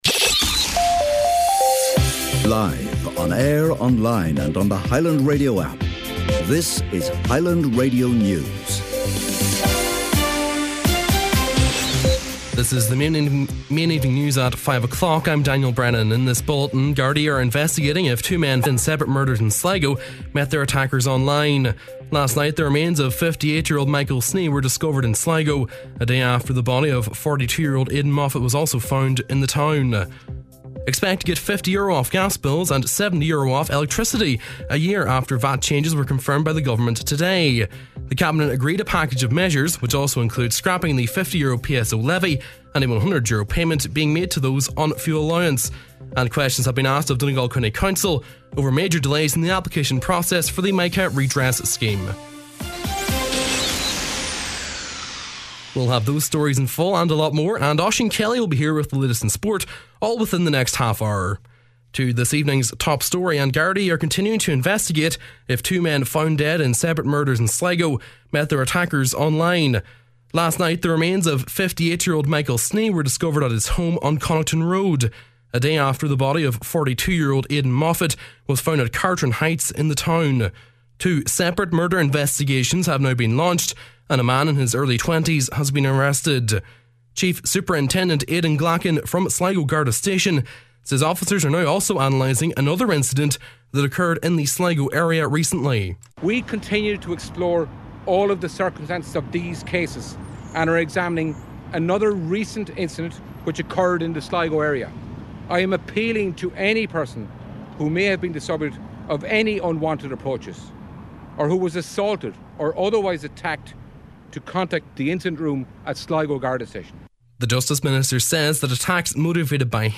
Main Evening News, Sport and Obituary Notices on Wednesday, April 13th